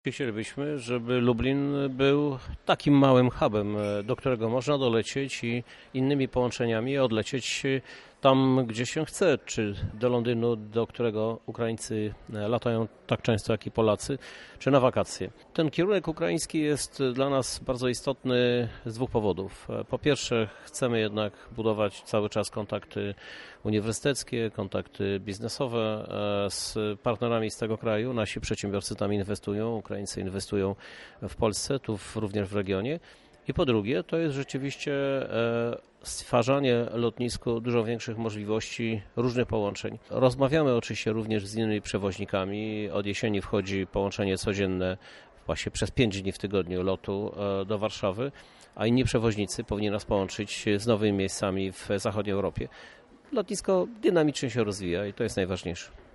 O przyszłości lotniska mówi prezydent Lublina Krzysztof Żuk.
K. Żuk